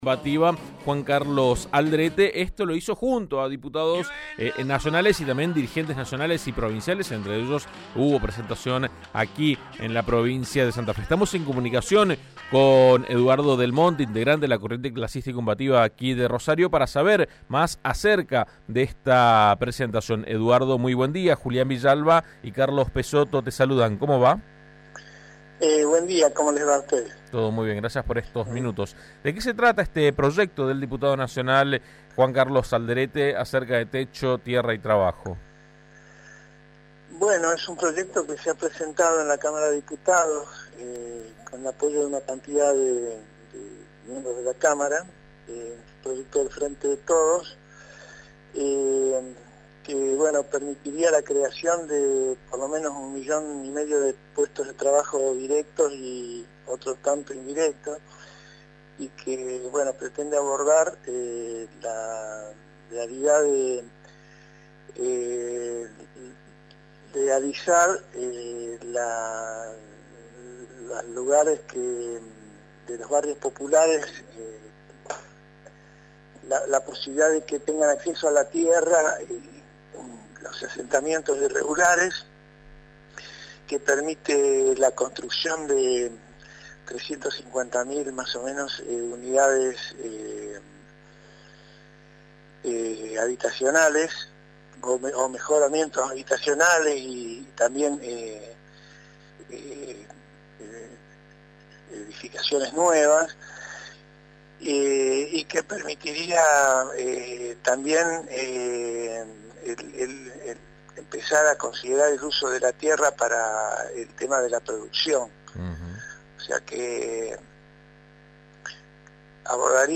dialogó al respecto con AM 1330.